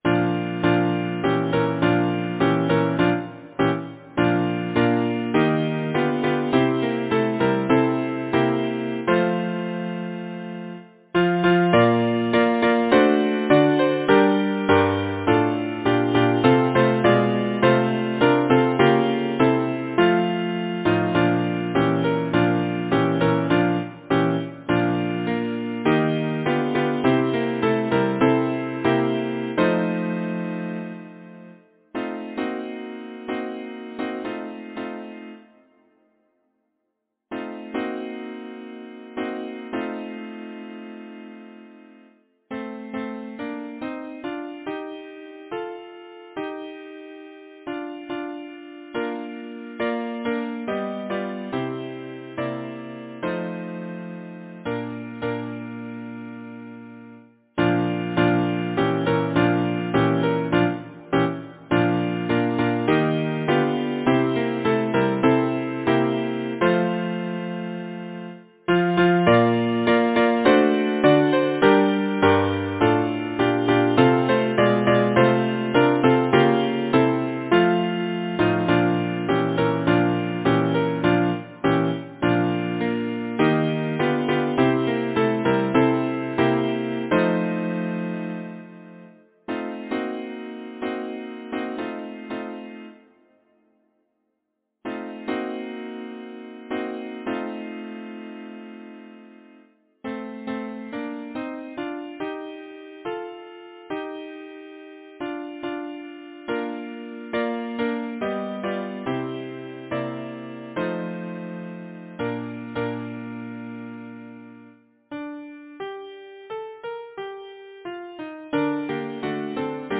Title: Aunt Margery Composer: James Asher Parks Lyricist: Frederic Edward Weatherly Number of voices: 4vv Voicing: SATB Genre: Secular, Partsong
Language: English Instruments: A cappella